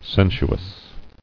[sen·su·ous]